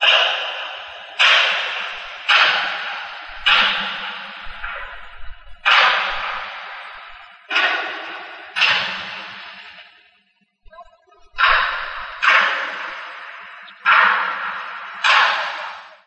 描述：从我住的波罗的海造船厂的窗户听到一些锤击声
Tag: 锤击 巴LTIC 金属命中 低保 遥远 建筑 造船 环境 噪音 回声呼应 金属 波罗的海造船厂 命中 工业